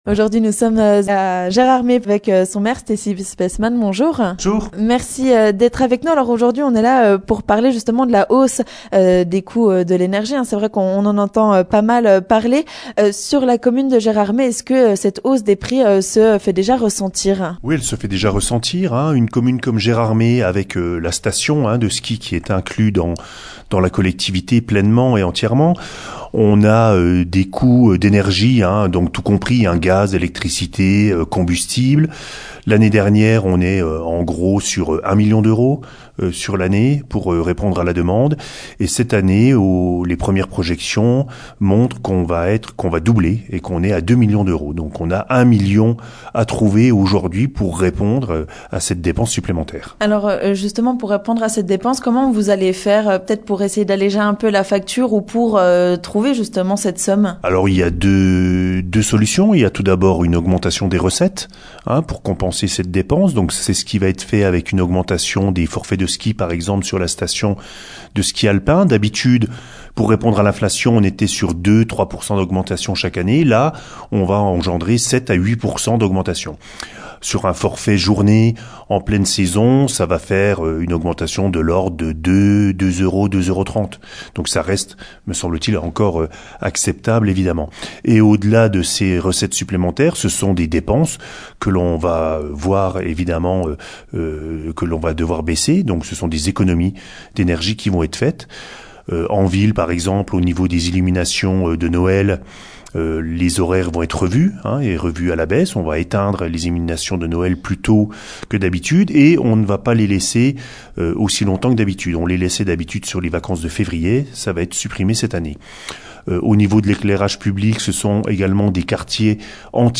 On fait le point avec le Maire de Gérardmer, Stessy Speissmann.